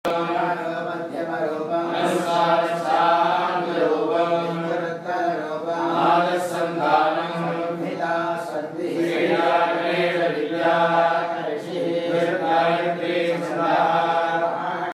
Juste avant notre départ, un groupe d’Indiens célèbrent un rituel dans le hall de notre hôtel, au son de leurs belles voix graves.
ceremonie_indiens.mp3